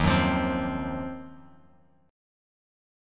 D#m6add9 chord